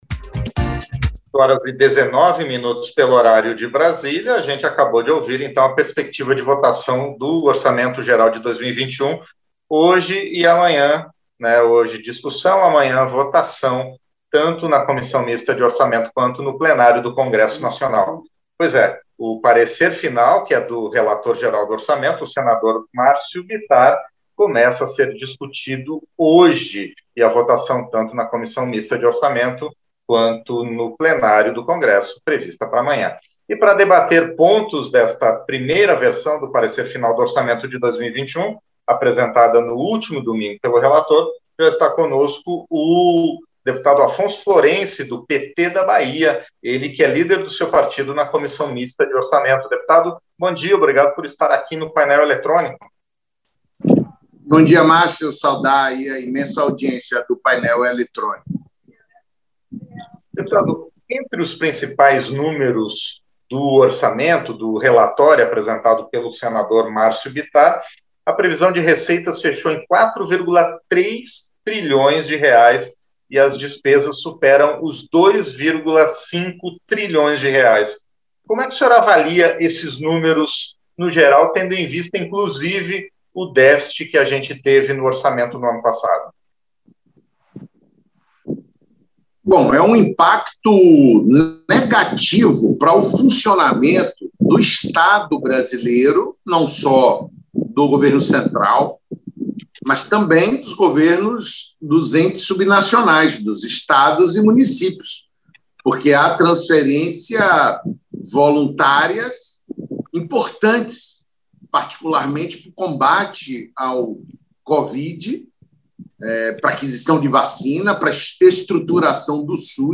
Entrevista - Dep. Afonso Florence (PT-BA)